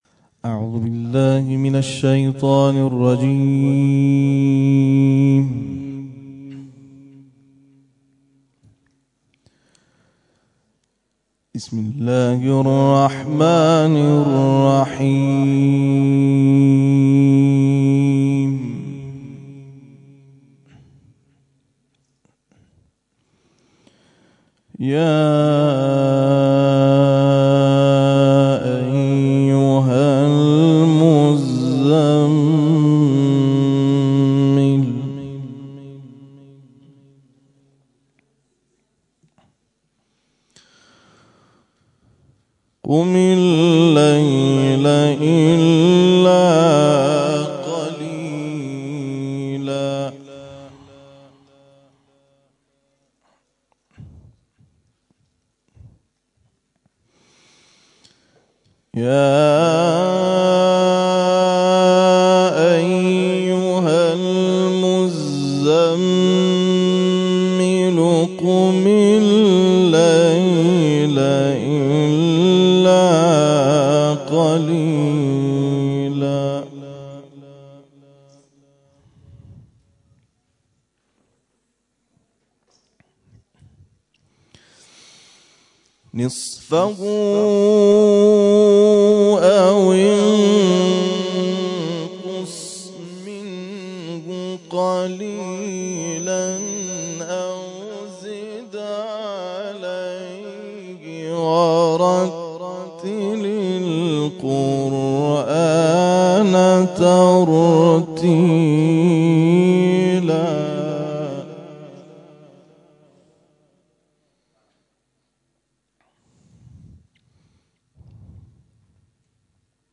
تلاوت
ابتهال
محفل انس با قرآن کریم به مناسبت عید مبعث
گروه تواشیح نورالساجدین، آخرین برنامه خود را همخوانی کرد